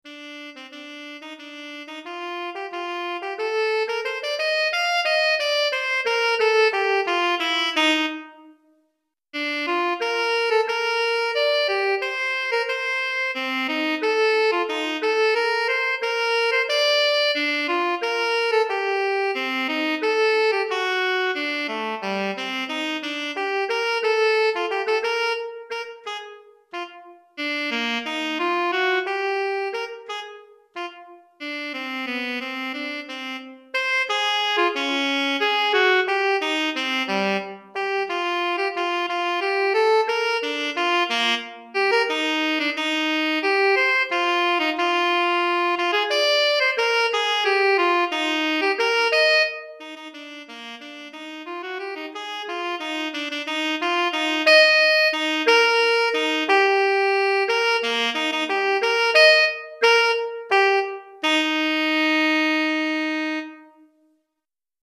Saxophone Alto Solo